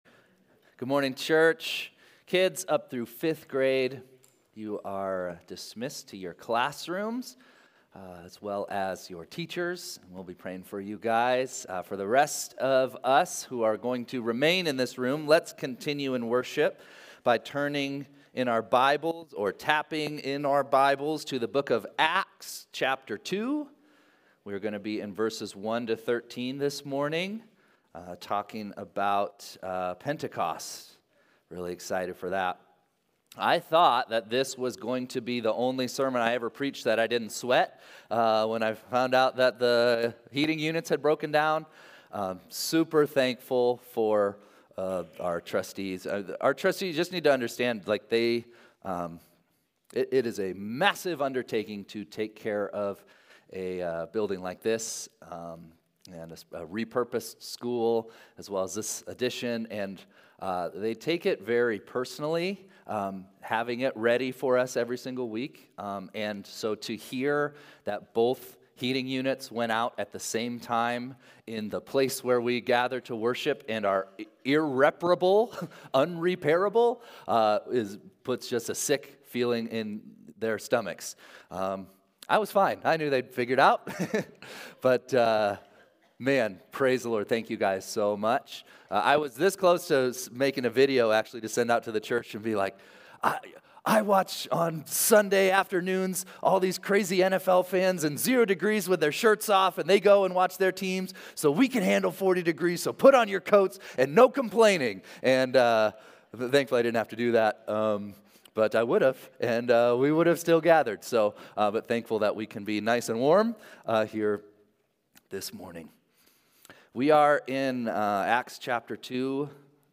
2-2-25-Sunday-Service.mp3